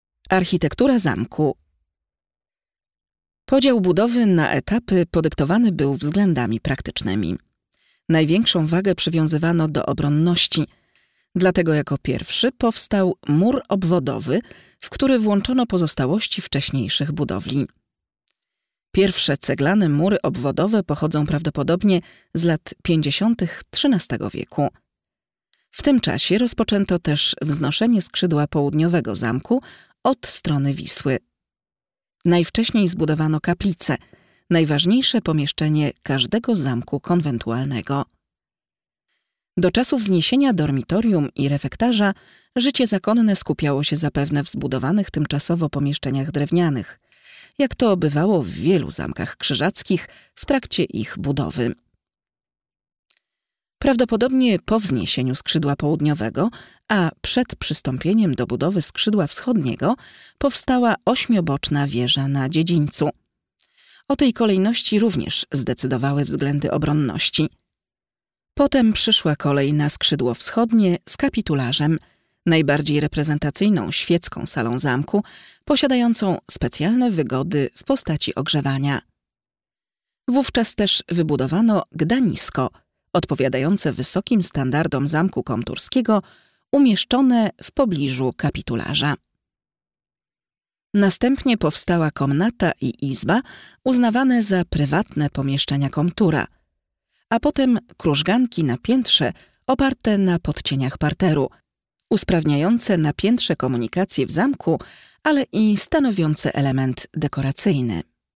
audioprzewodnik_12-architektura-zamku-w-toruniu.mp3